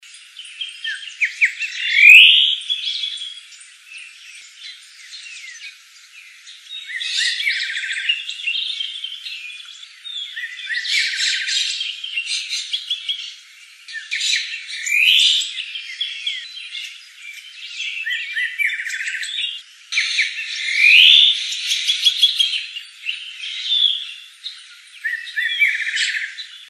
Bluish-grey Saltator (Saltator coerulescens)
Life Stage: Adult
Condition: Wild
Certainty: Observed, Recorded vocal